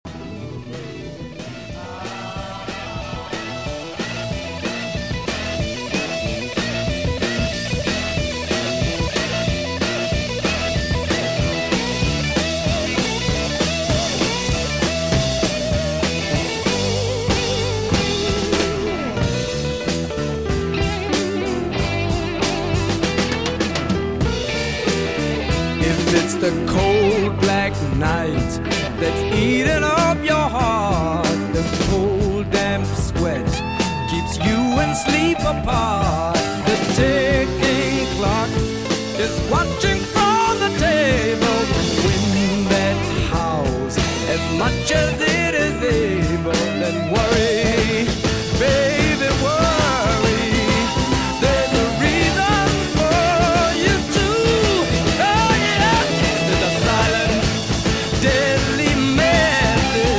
Рок
специализировавшихся на блюз-роке.